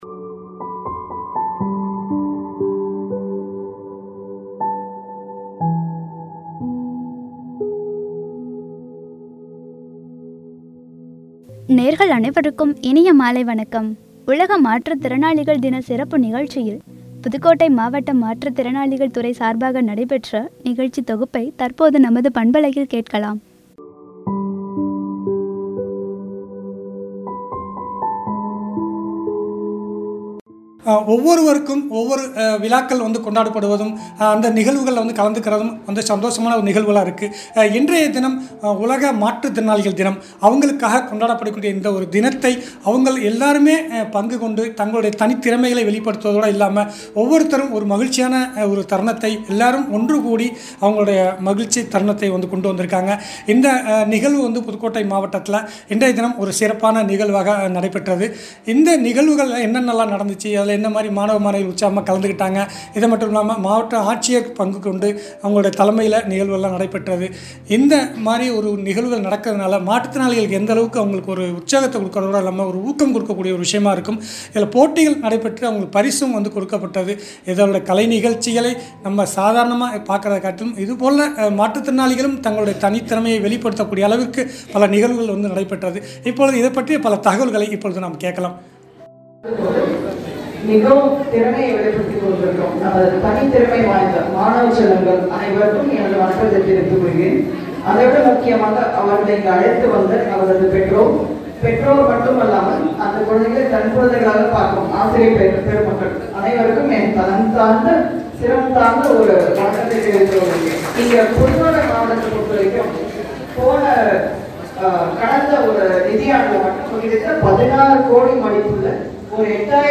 புதுக்கோட்டை மாவட்ட மாற்றுத்திறனாளிகள் துறை சார்பாக நடைபெற்ற நிகழ்ச்சி தொகுப்பு குறித்து வழங்கிய உரையாடல்கள்.